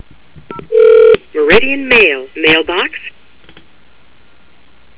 Typical login message Main Keypad